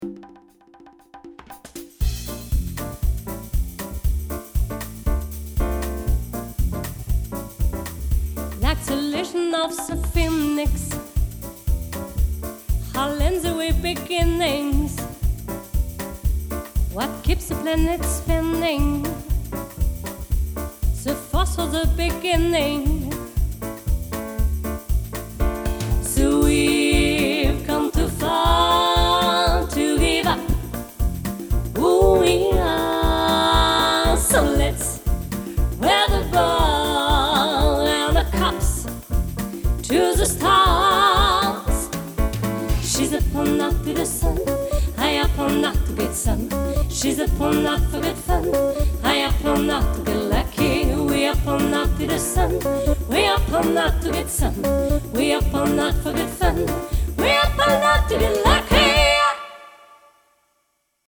Chanteuse
- Mezzo-soprano
chant